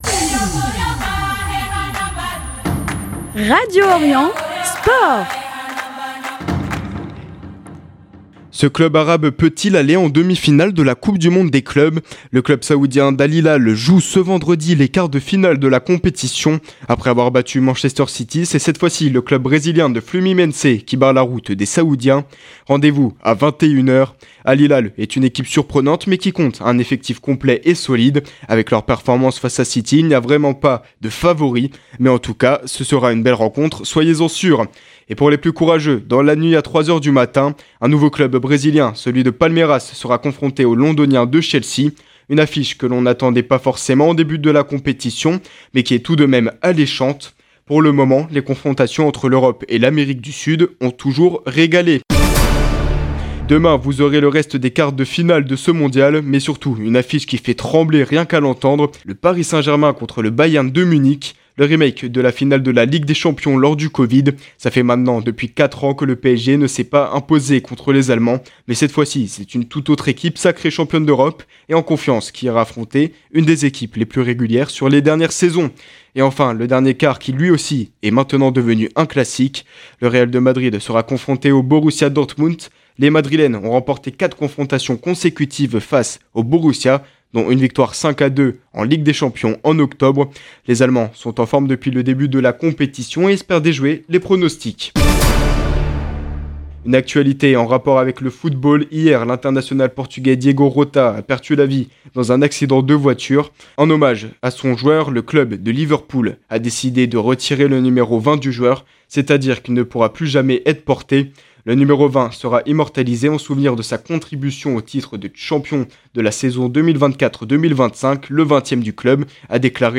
Journal des Sports 4 juillet 2025